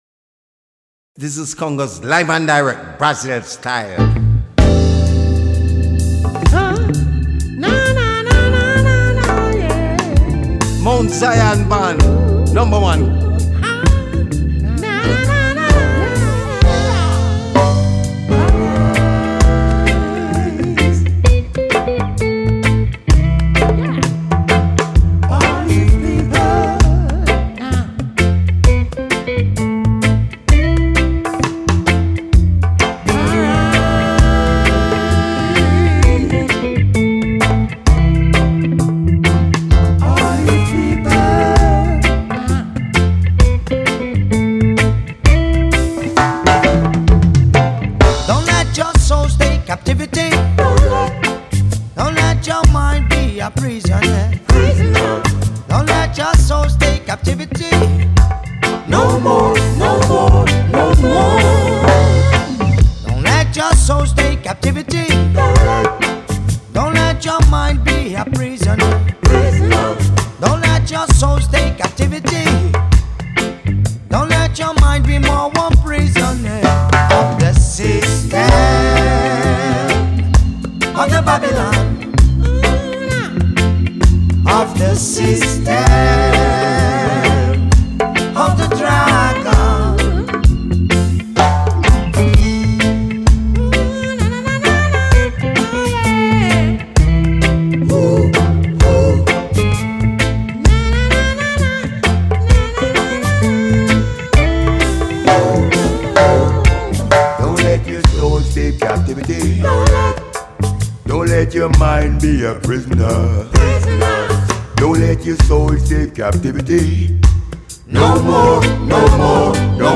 A lendária banda de reggae jamaicana